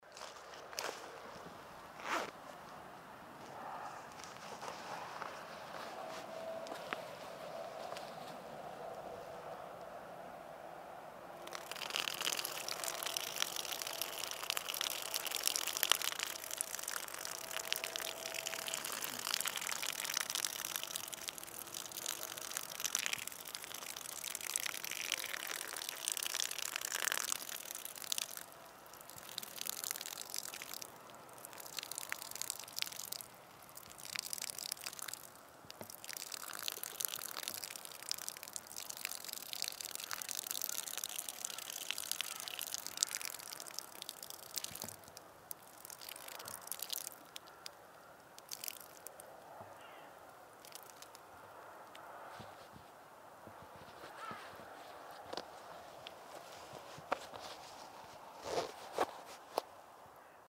• Качество: высокое
Сцание человека на улице прямо на землю